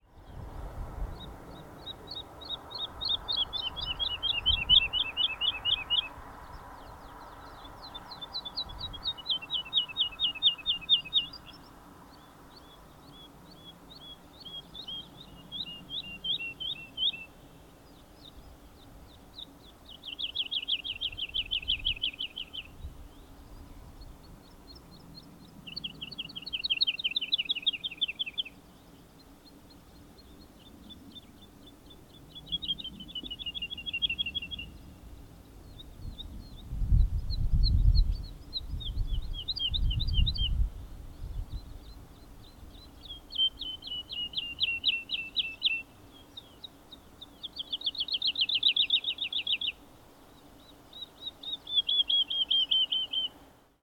Op weg naar het bezoekerscentrum hielden we oren en ogen natuurlijk goed open en daarom hoorden we op een aantal plekken de heerlijke zang van de Boomleeuwerik. Gelukkig was het redelijk rustig in het Park en kan ik vanuit de auto, afgeschermd voor de wind, heel duidelijke geluidsopnamen maken. De enige stoorzender was eigenlijk een Boompieper die ook aardig van zich liet horen.
De melodieuze Boomleeuwerk
Boomleeuwerik3.mp3